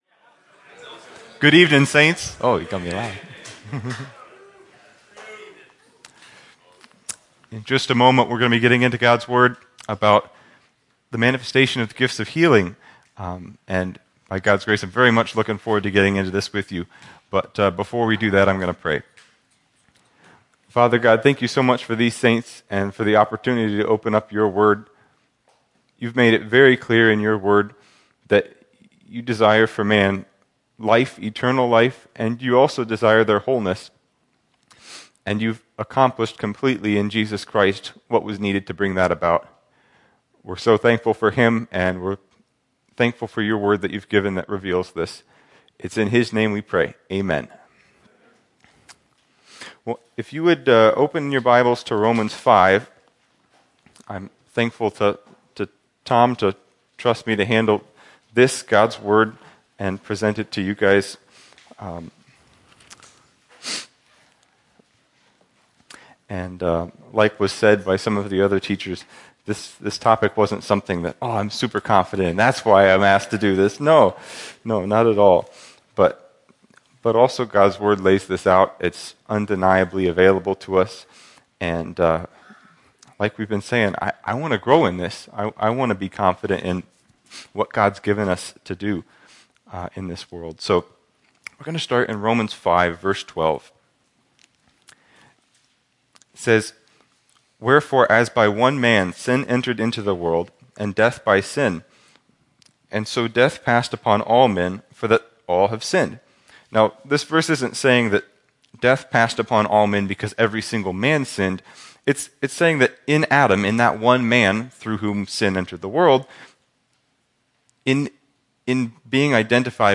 An introductory teaching on various aspects of the healing that is ours in Christ taken from the Men’s Weekend, “Greater Works”.